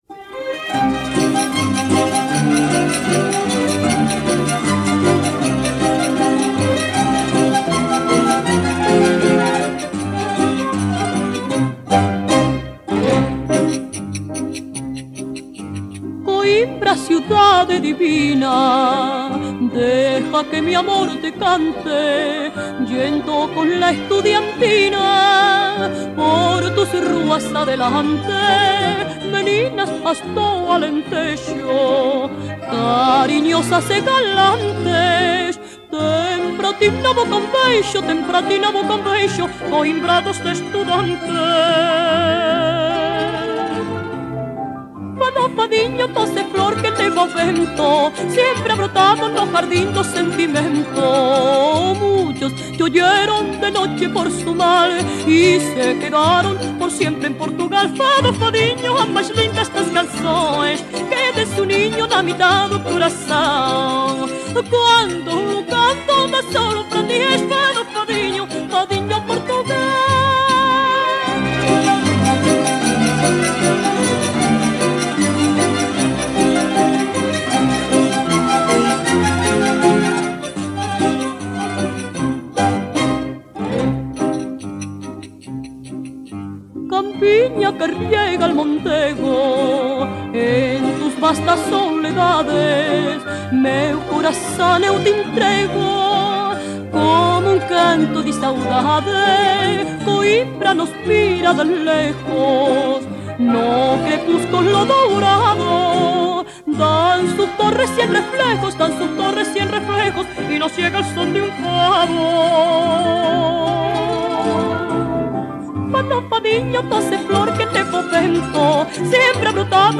Пела испанская или португальская певица.